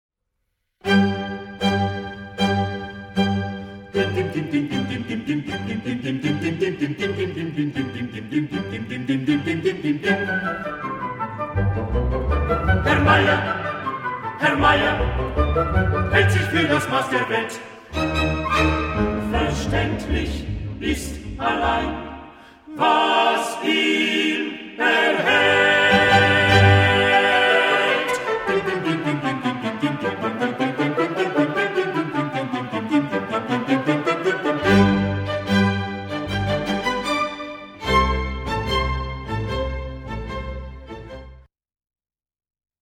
Countertenor
Tenor
Bassbariton
Bass
Klavier